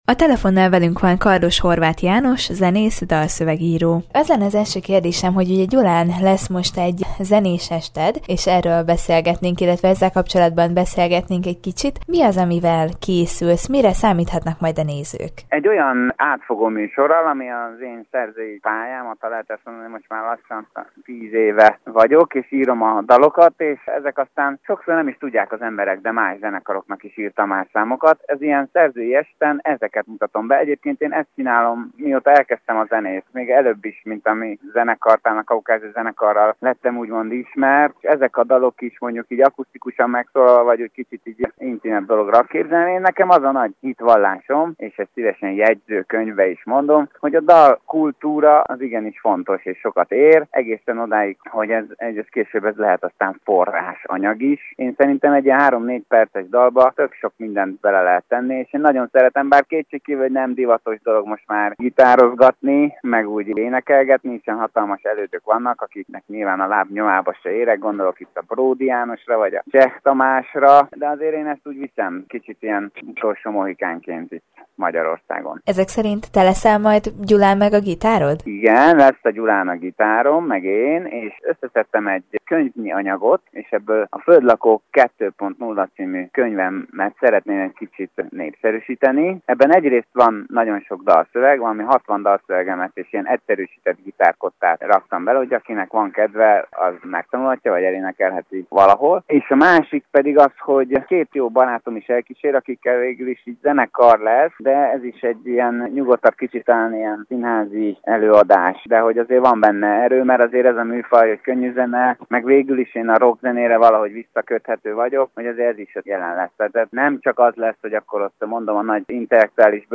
Post navigation Előző hír Évekkel ezelőtt megálmodtam, hogy egy nap hazaköltözünk Következő hír Évről-évre több az érdeklődő a koncertjeinken KATEGÓRIA: Interjúk